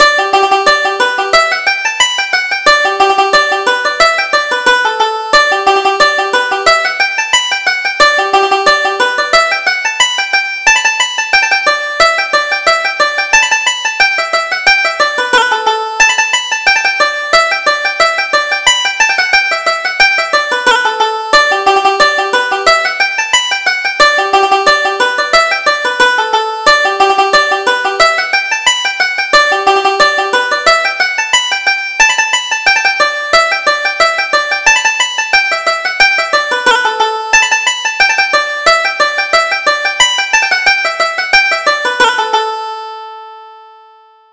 Reel: Going to the Fair